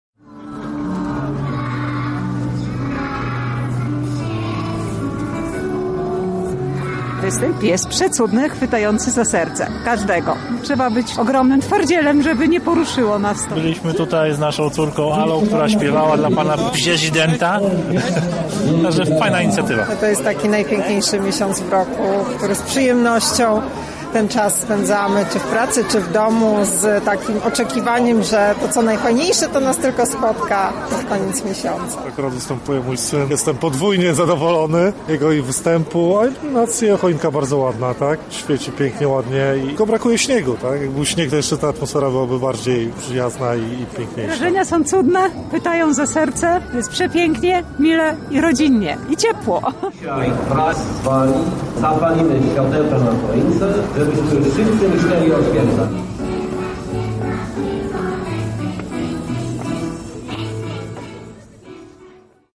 Na miejscu był nasz reporter:
Relacja z wydarzenia